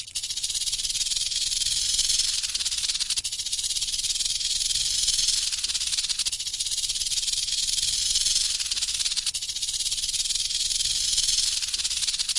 Tiếng kêu của Rắn Đuôi Chuông
Thể loại: Tiếng động vật hoang dã
Description: Hiệu ứng âm thanh đặc trưng, sống động, mô phỏng tiếng rung đuôi cảnh báo của loài rắn đuôi chuông (còn gọi là rắn chuông, rắn rung đuôi, rattlesnake). Âm thanh này được tạo ra khi rắn lắc mạnh phần đuôi có chứa các khoang rỗng va đập vào nhau, phát ra tiếng rung rít liên tục, nghe như tiếng lách cách, rè rè, xè xè, có âm sắc sắc bén và căng thẳng.
tieng-keu-cua-ran-duoi-chuong-www_tiengdong_com.mp3